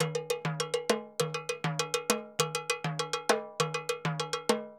Timbaleta_Baion 100_2.wav